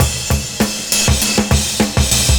100CYMB05.wav